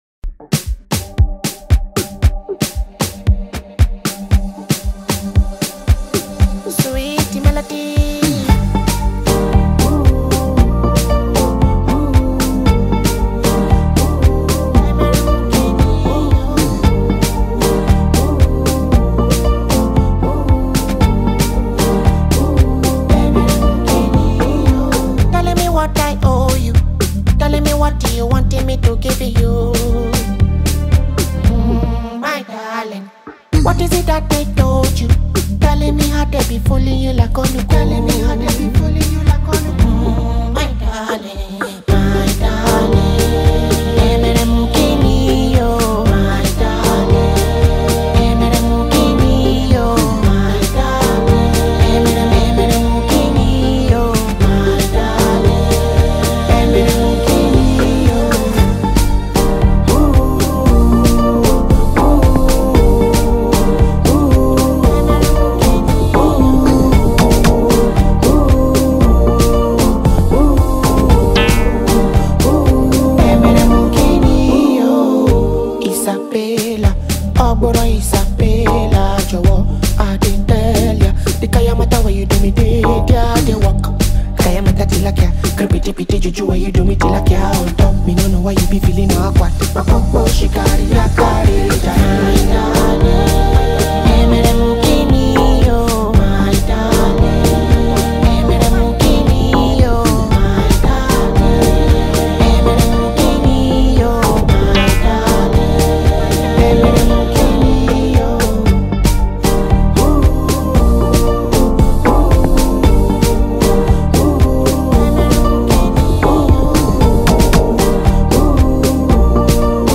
” a track that seamlessly fuses Afrobeat with romantic soul.